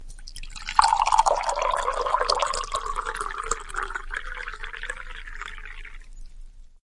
饮食 " 浇灌冷茶
Tag: 填充 填充 玻璃 倾倒 饮料 液体 浇注